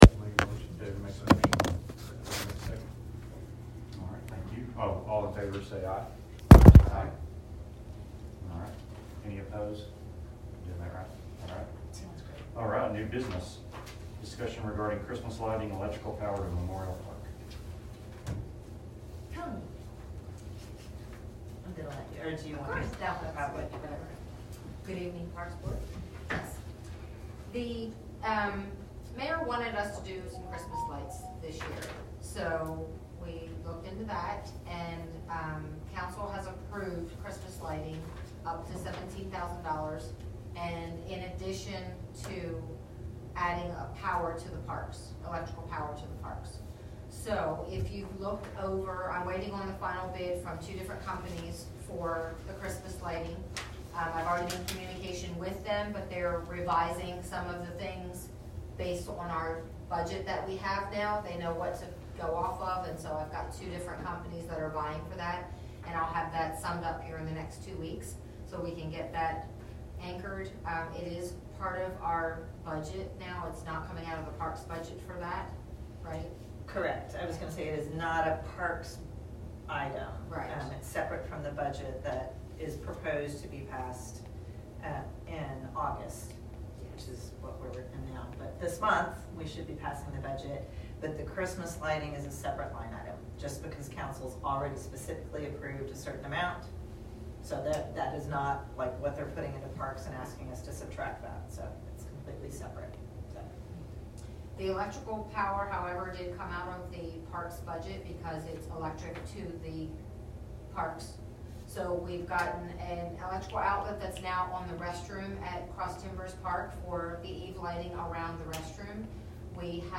01 August 2022 Parks Board Meeting
6 P.M. / 516 Ranch House Rd, Willow Park, TX 76087